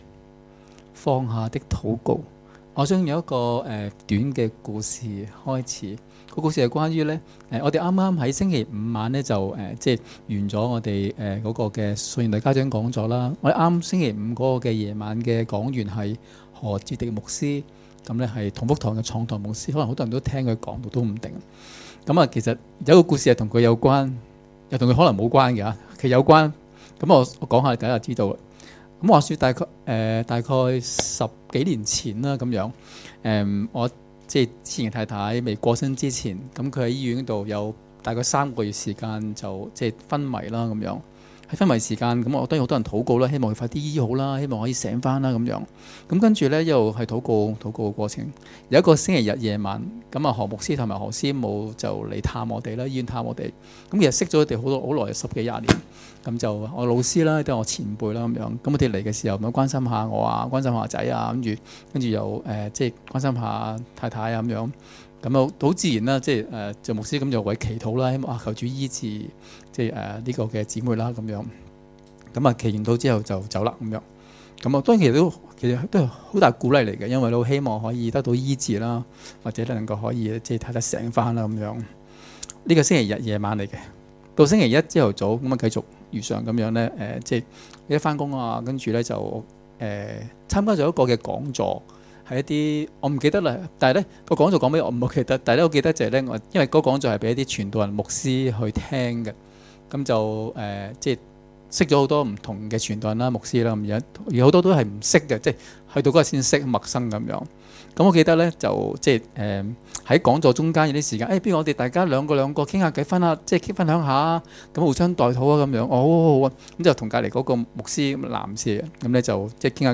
2019年5月18日及19日崇拜